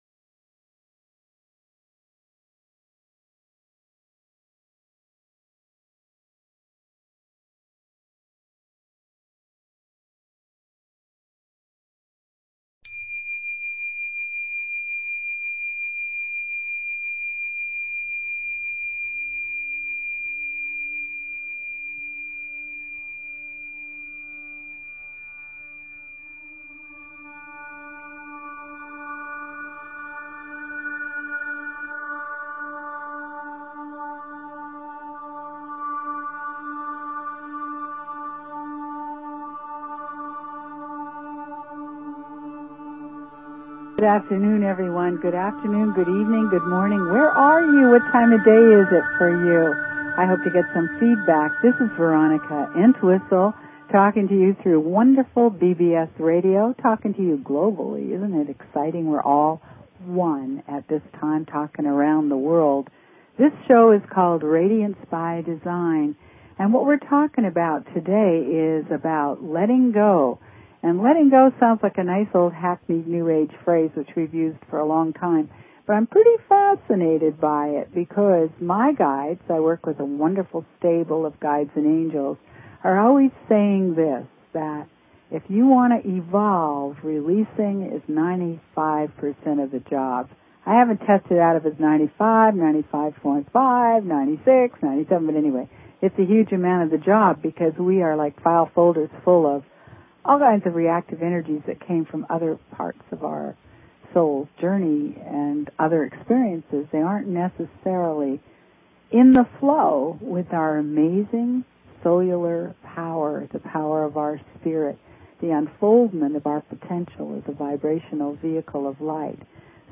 Talk Show Episode, Audio Podcast, Radiance_by_Design and Courtesy of BBS Radio on , show guests , about , categorized as
Show Headline Radiance_by_Design Show Sub Headline Courtesy of BBS Radio Radiance by Design - January 27, 2009 Radiance By Design Please consider subscribing to this talk show.